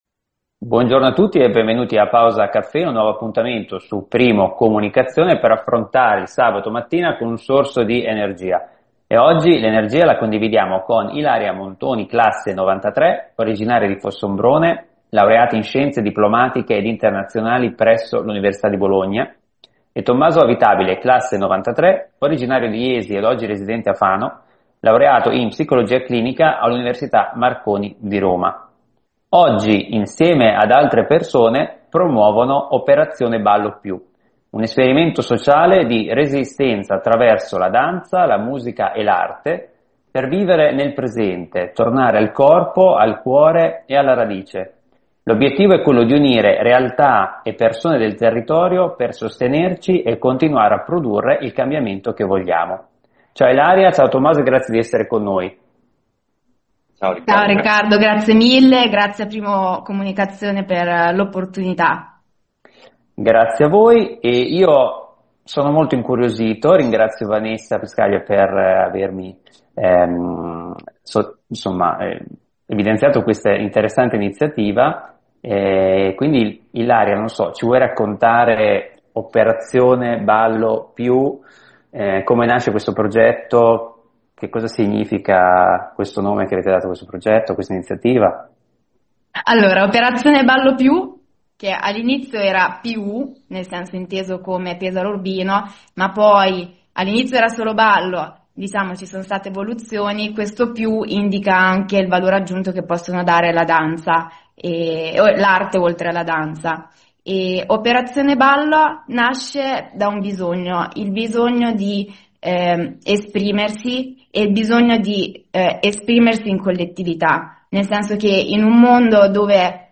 Nell’intervista per Pausa Caffeina